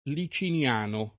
[ li © in L# no ]